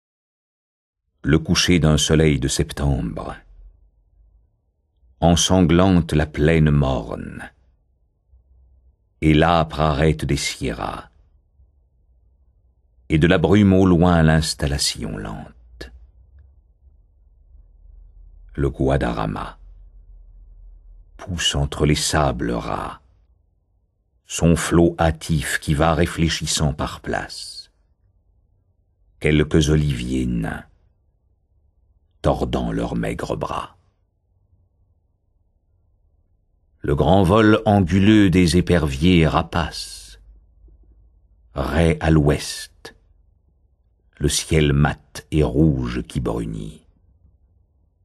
Extrait gratuit - La mort de Philippe II d'Espagne de Paul Verlaine